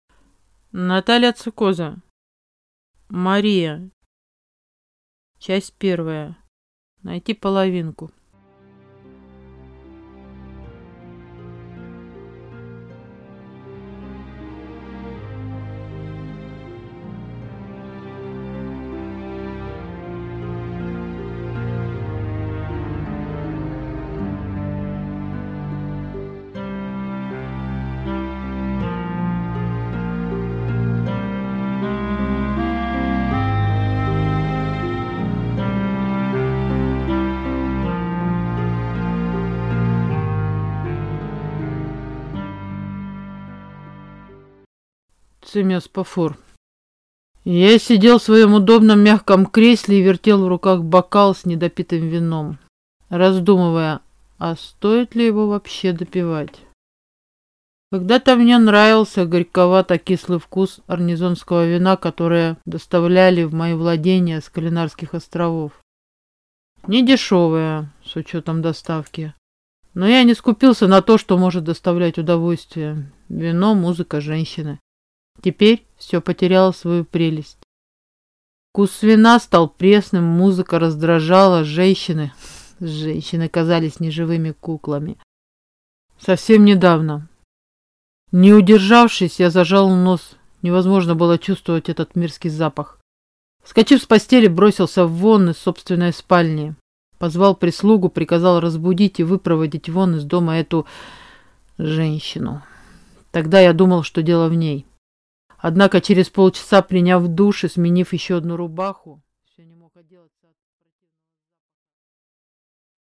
Аудиокнига Мария. Часть 1. Найти половинку | Библиотека аудиокниг